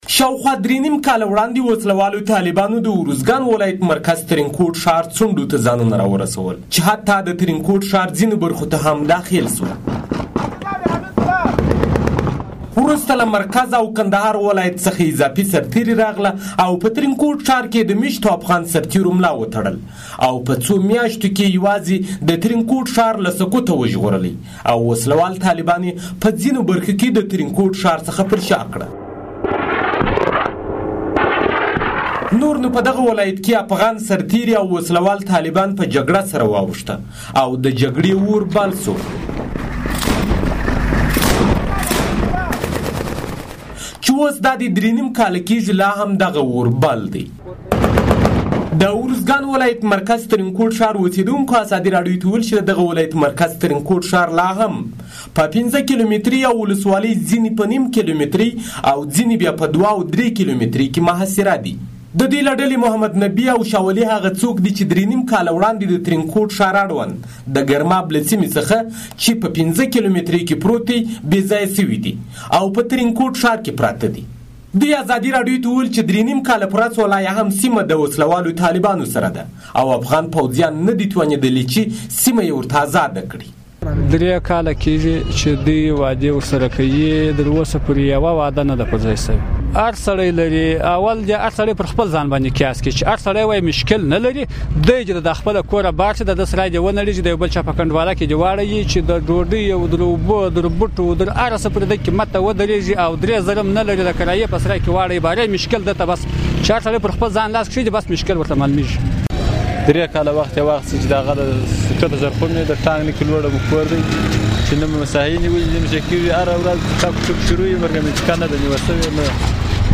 دوی ازادي راډيو ته وویل چې درې کال کېږي له خپلو سیمو بې ځايه دي.